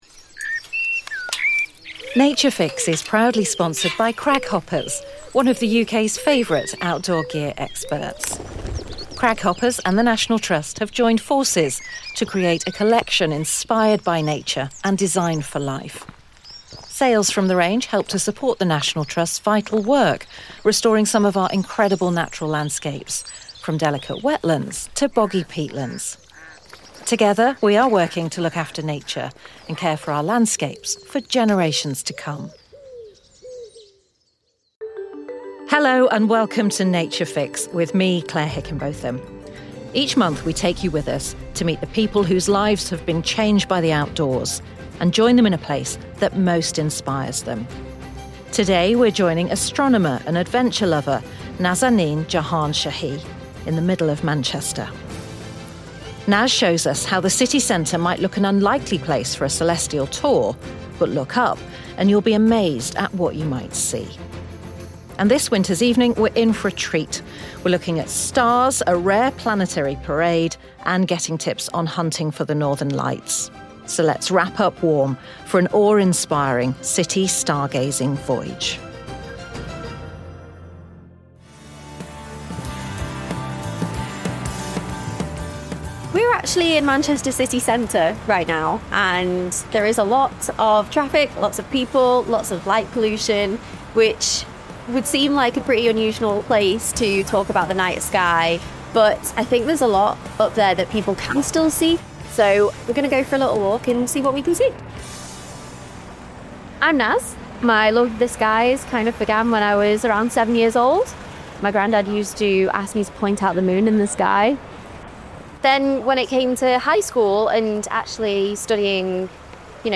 Wrap up warm and turn your eyes to the skies for a stargazing tour from the middle of Manchester.